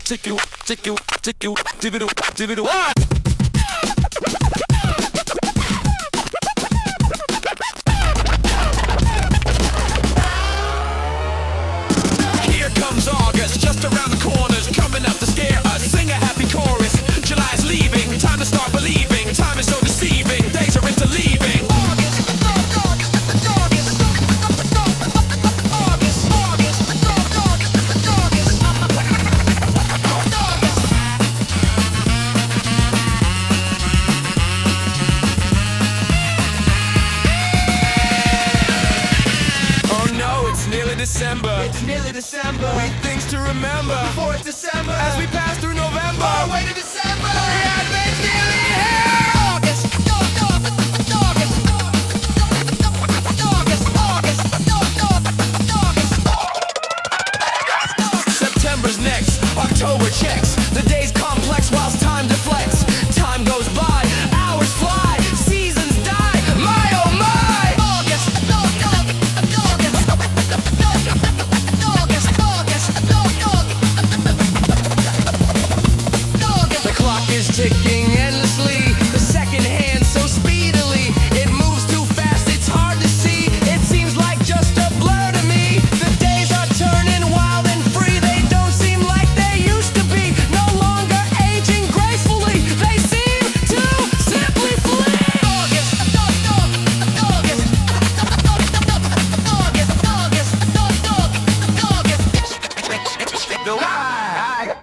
Sung by Suno
August_Incoming_(Remix)-2_mp3.mp3